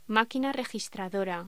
Locución: Máquina registradora
voz
Sonidos: Hostelería